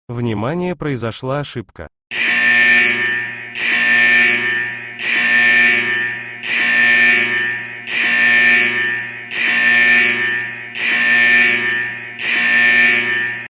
Звуки ошибок
На этой странице собраны разнообразные звуки ошибок из операционных систем, программ и игр.